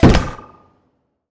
sci-fi_weapon_pistol_shot_03.wav